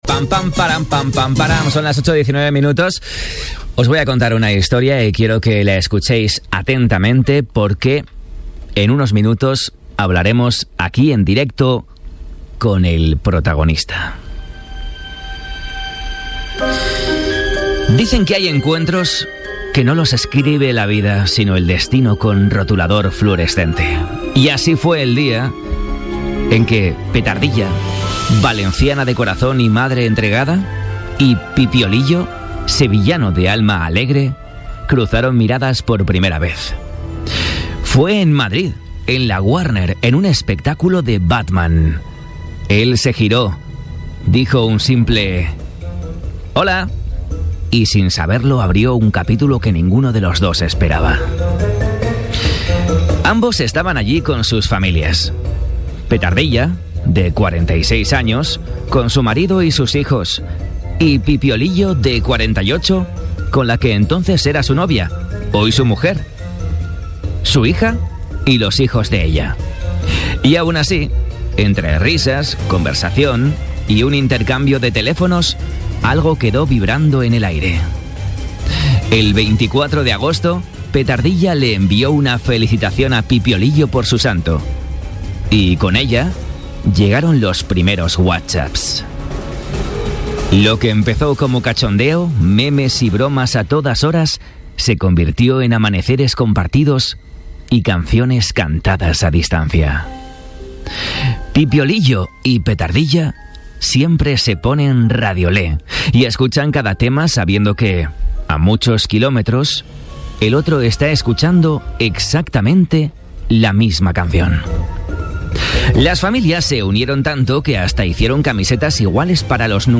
Una pipiola confiesa su infidelidad en directo… ¡y pasa esto!